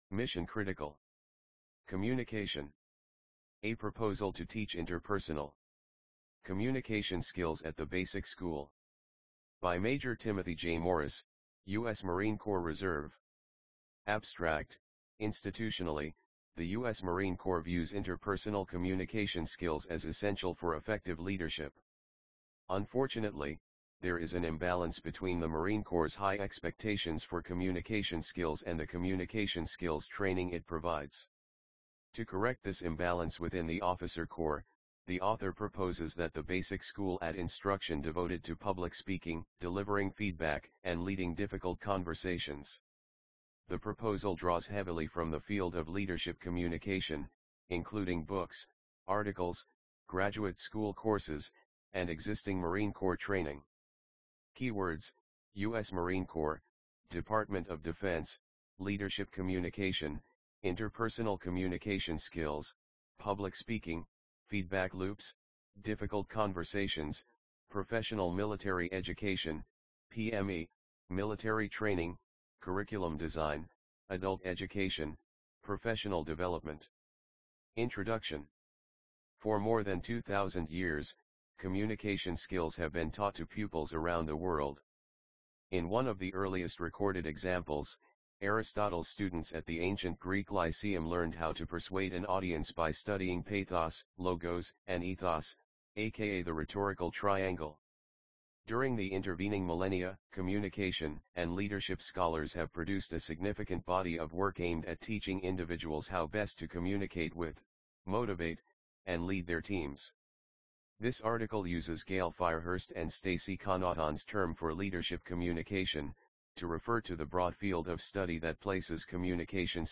IPME 2025_Misson-Critical Communication_AUDIOBOOK.mp3